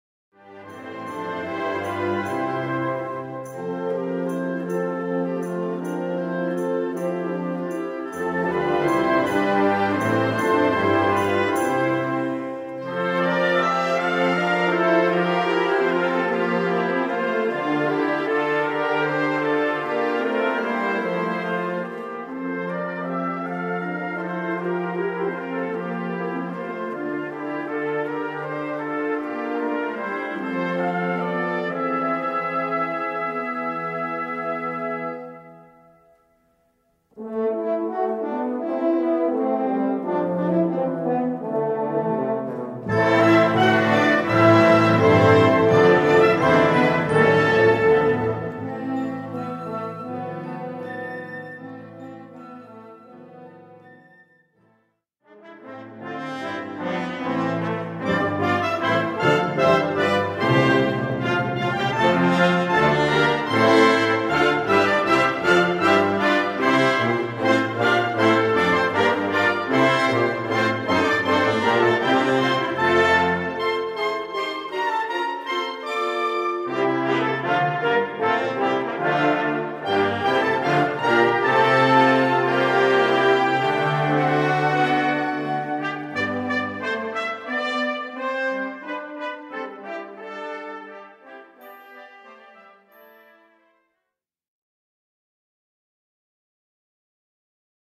Gattung: Weihnachten
6:00 Minuten Besetzung: Blasorchester Zu hören auf